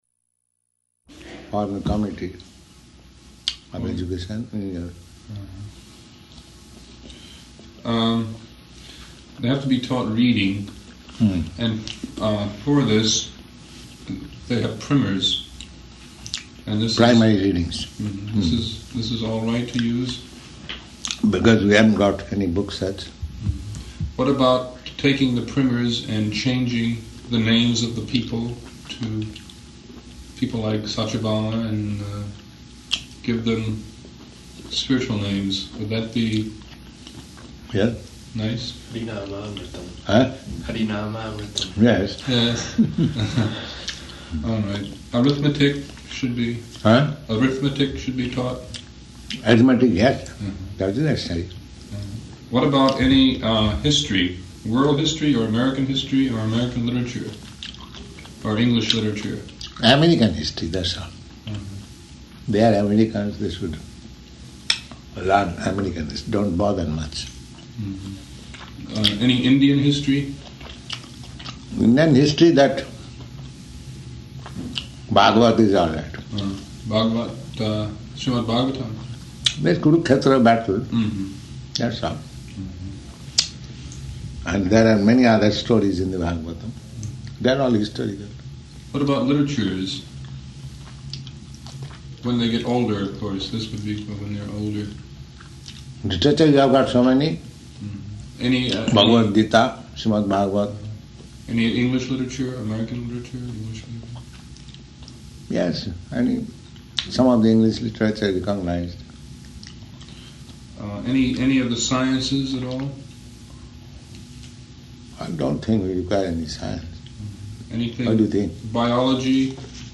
Discussion about New Vrindavan Gurukula
Location: Boston